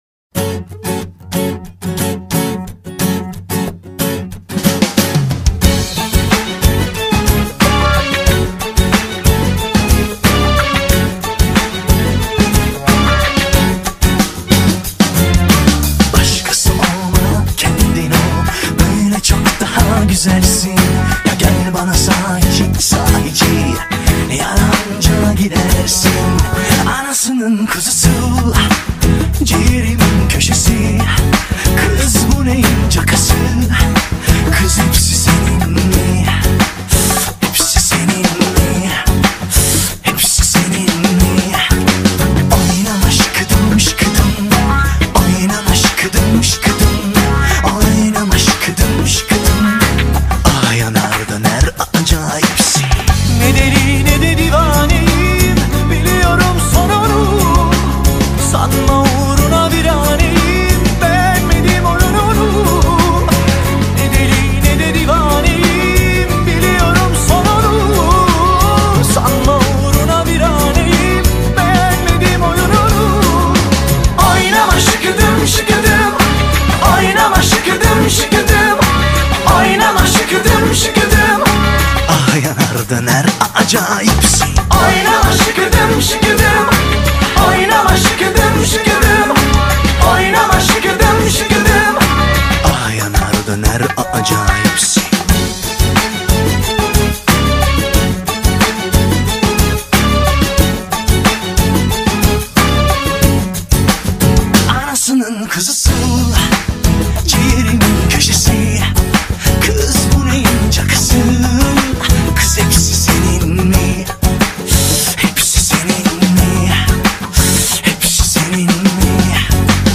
Turkish Pop, Pop, Dance-Pop